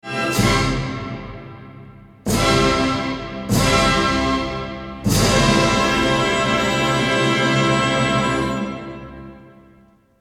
Là je me permets une digression car l’attitude du public est affligeante, des applaudissements avant la fin, à cause de quelques silences. Mais justement les silences c’est aussi de la musique, voici comment se termine l’ouverture :